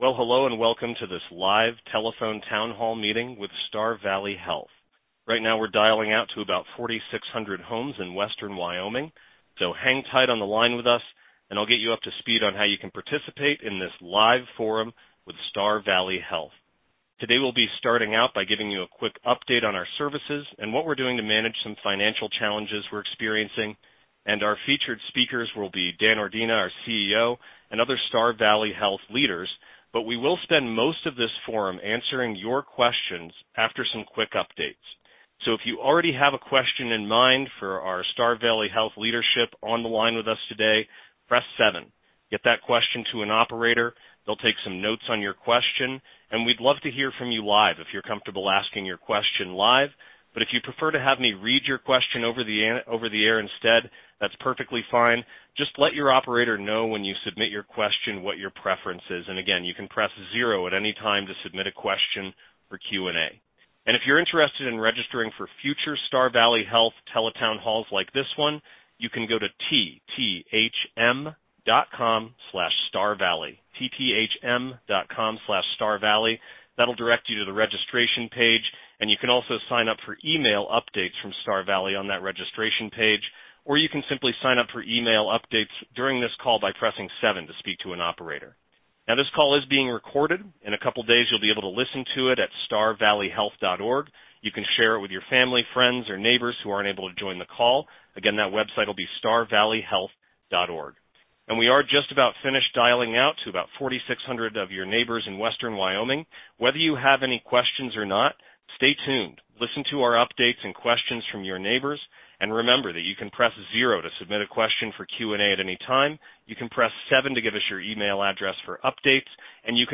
Star Valley Health Telephone Town Hall
Our live telephone town hall took place May 8.
Questions were asked live from our callers as well as pre-submitted with registration.